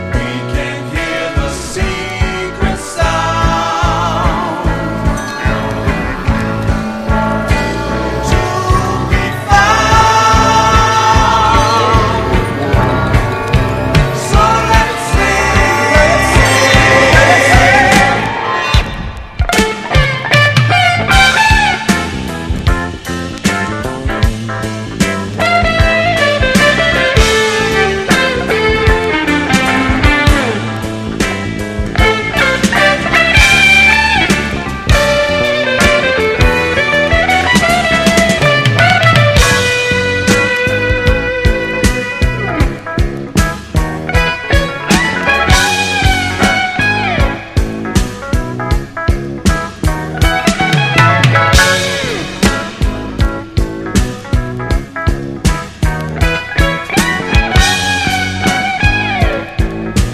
JAPANESE DISCO / KIDS DISCO (JPN)
ファンキーな和モノ・キッズ・ディスコ歌謡7インチ！